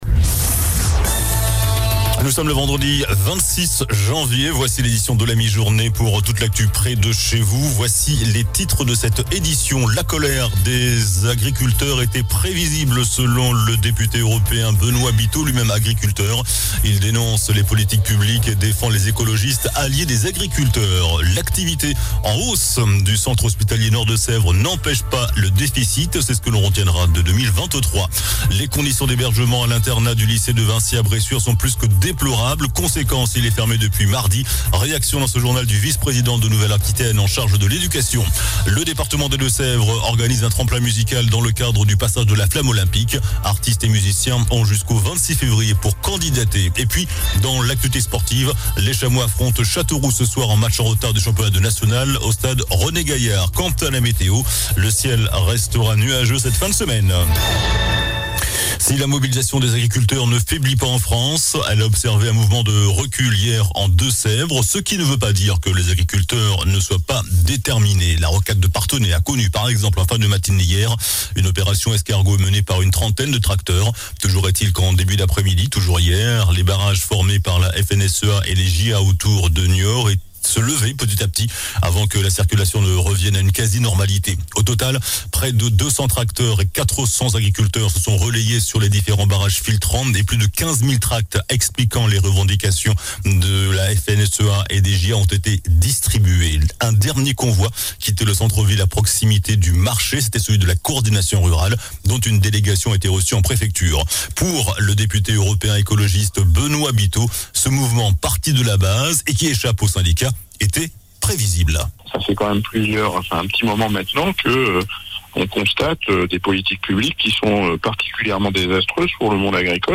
JOURNAL DU VENDREDI 26 JANVIER ( MIDI )